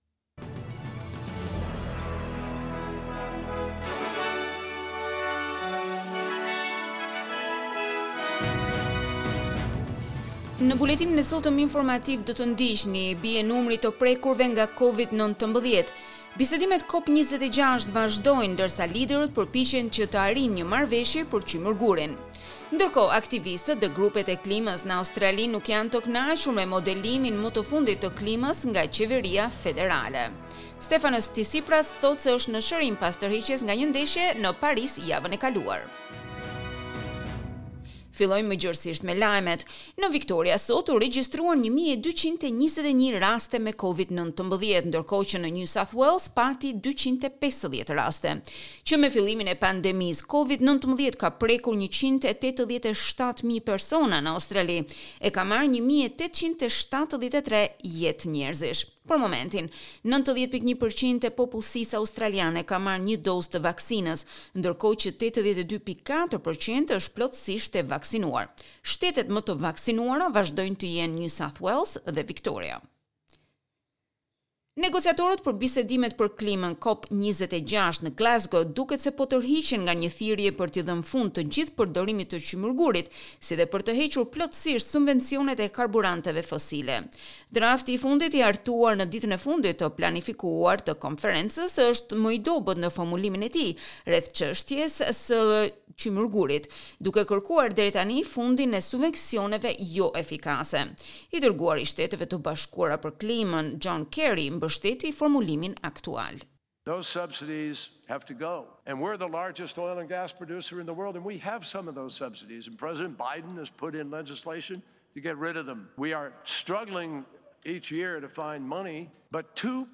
SBS News Bulletin in Albanian - 13 November 2021